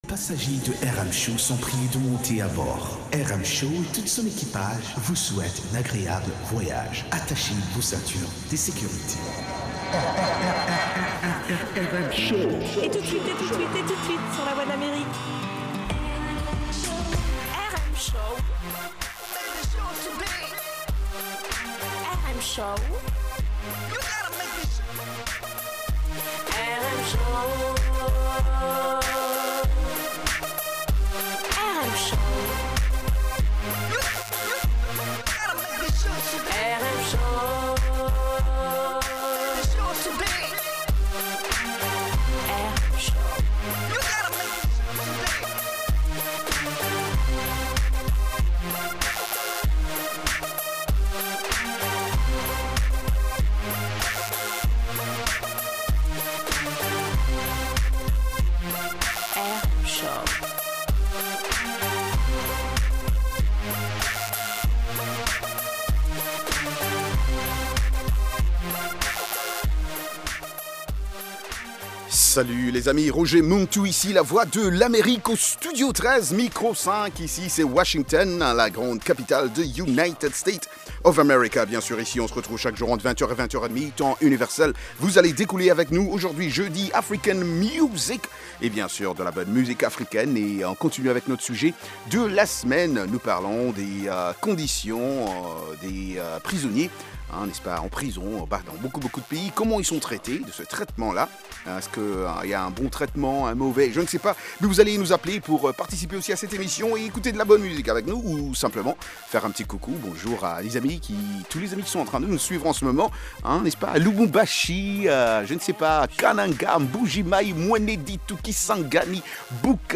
propose notamment d'écouter de la musique africaine, des articles sur l'actualité Afro Music, des reportages et interviews sur des événements et spectacles africains aux USA ou en Afrique.